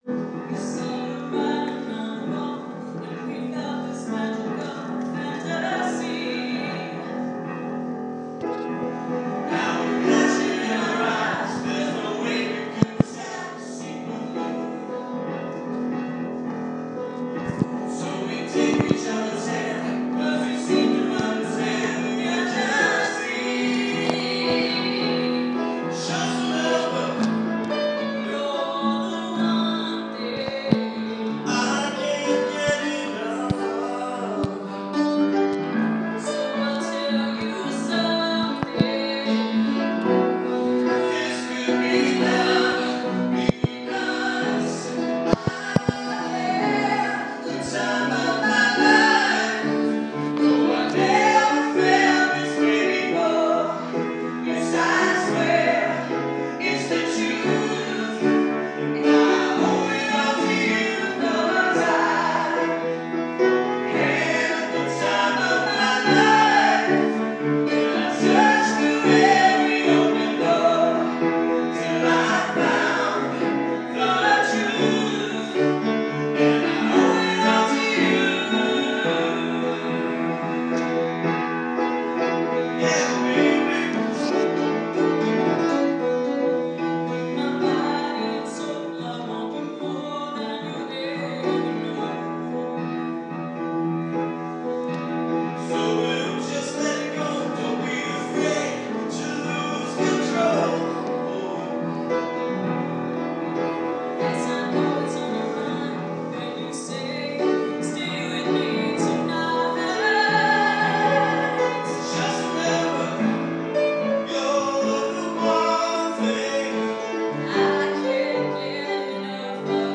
at the Unitarian Universalist Congregation in Andover